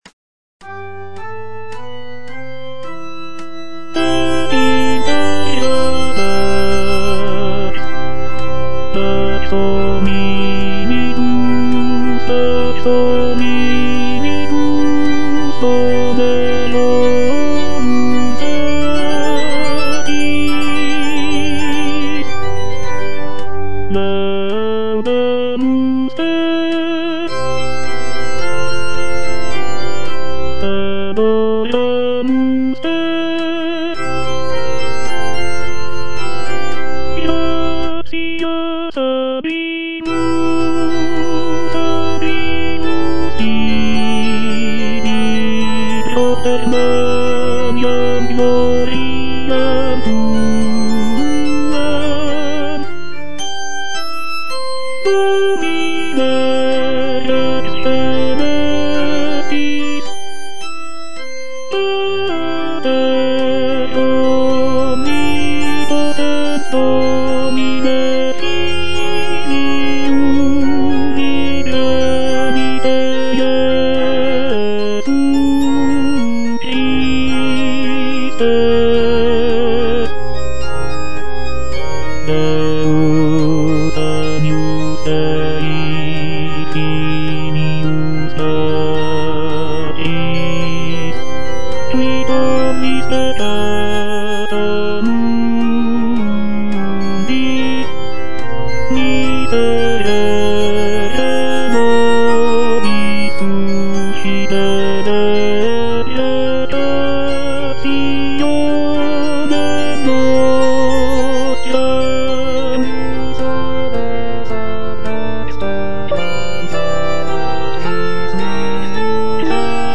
J.G. RHEINBERGER - MASS IN C OP. 169 Gloria - Tenor (Voice with metronome) Ads stop: auto-stop
It is composed for SATB choir, soloists, organ, and orchestra.
This composition is known for its rich textures, expressive melodies, and intricate interplay between the vocal and instrumental sections, making it a notable contribution to the genre of sacred choral music.